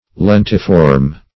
Lentiform \Len"ti*form\ (l[e^]n"t[i^]*f[^o]rm), a. [L. lens,